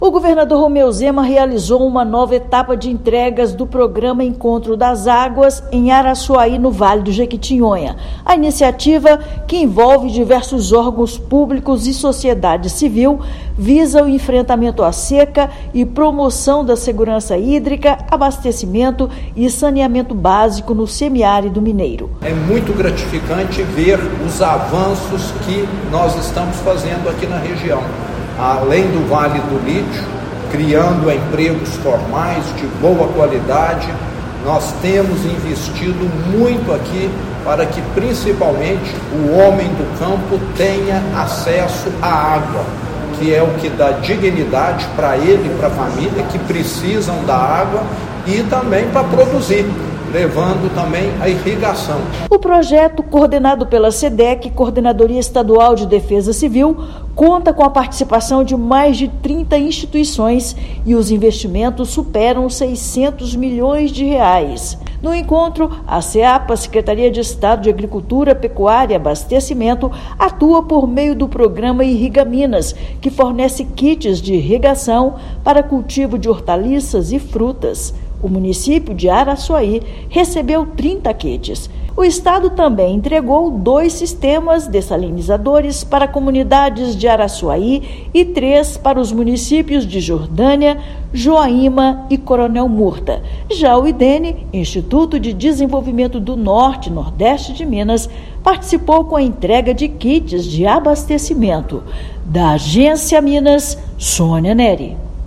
[RÁDIO] Governo de Minas entrega ações do programa Encontro das Águas em Araçuaí para beneficiar a população do semiárido do estado
Investimentos em abastecimento, saneamento, irrigação e assistência social beneficiam milhares de famílias das regiões Norte e dos vales do Jequitinhonha e do Mucuri. Ouça matéria de rádio.